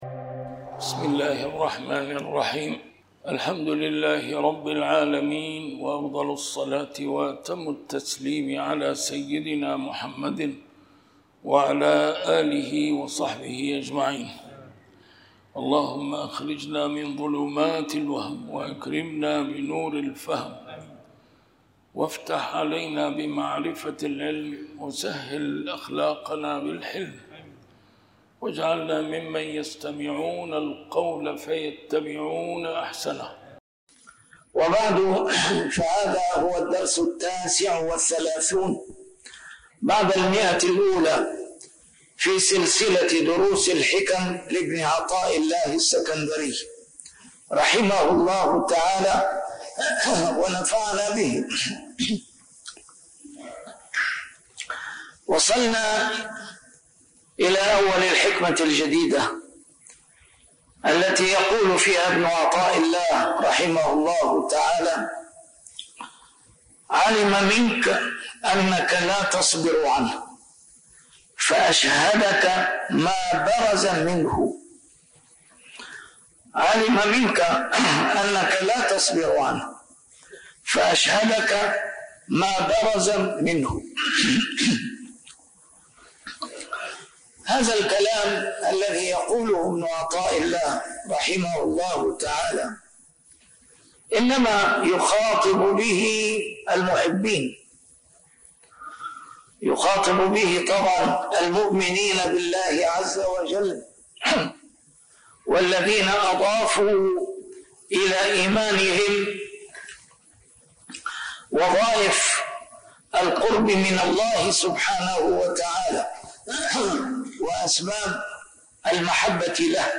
نسيم الشام › A MARTYR SCHOLAR: IMAM MUHAMMAD SAEED RAMADAN AL-BOUTI - الدروس العلمية - شرح الحكم العطائية - الدرس رقم 139 شرح الحكمة 117